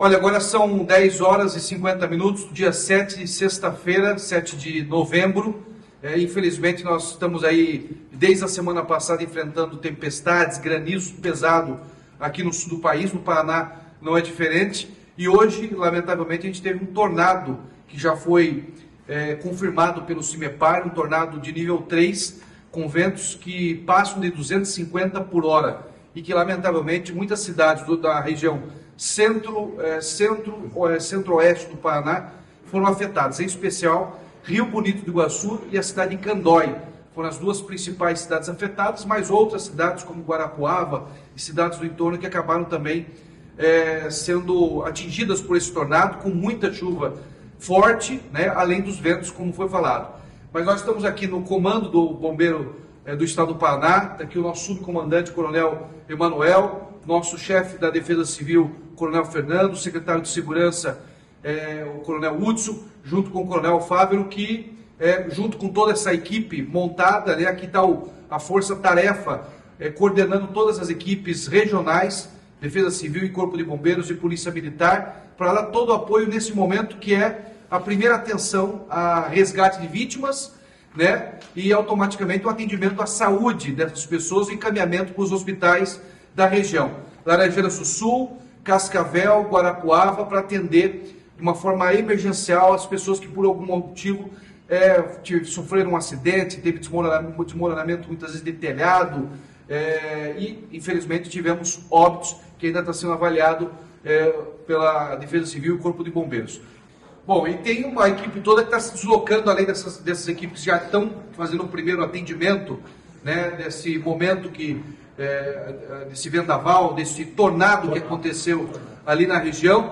Sonora do governador Ratinho Junior sobre o primeiro atendimento aos municípios atingidos por tornado